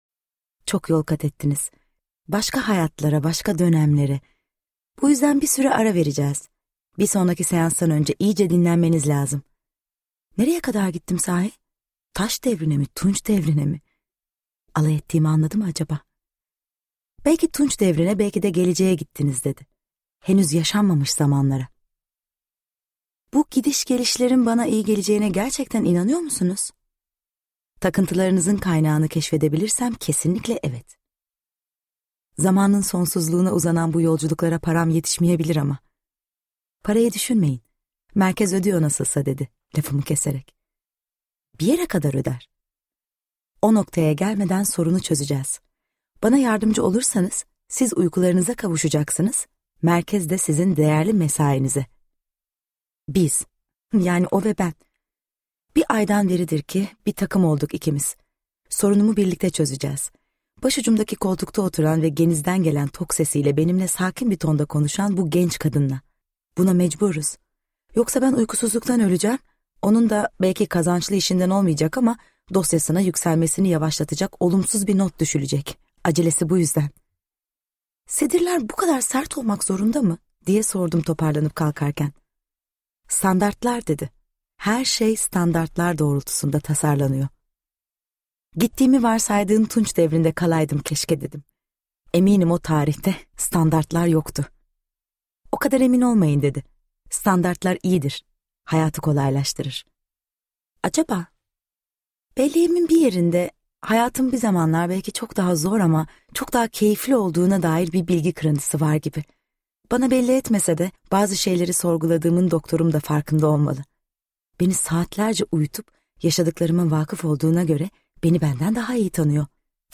Tutsak Güneş - Seslenen Kitap
Seslendiren
SEVİNÇ ERBULAK